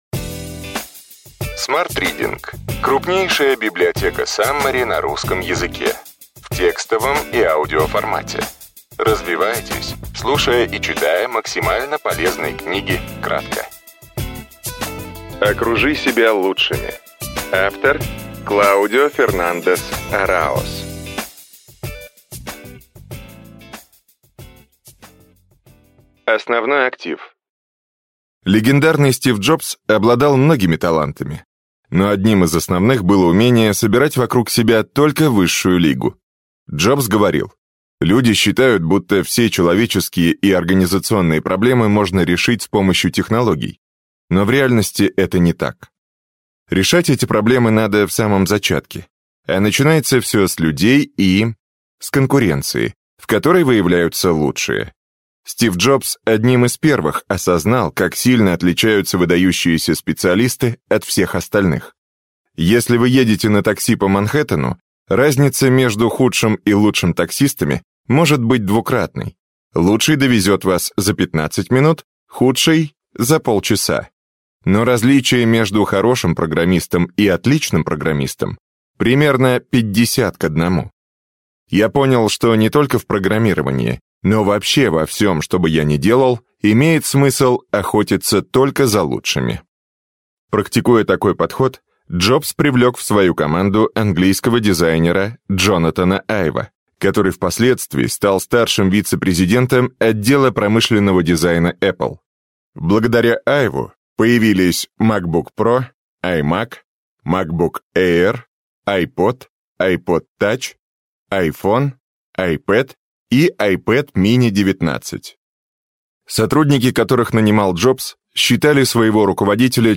Аудиокнига Окружи себя лучшими.